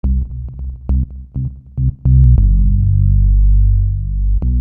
Bass 14.wav